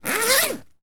foley_zip_zipper_long_01.wav